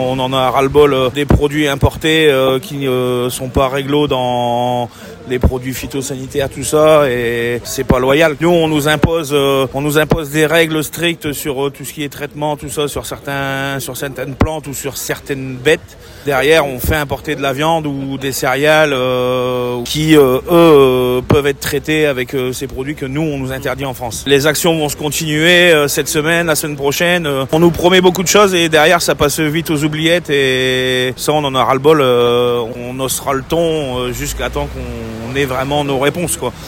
Nous sommes allés à la rencontre des manifestants .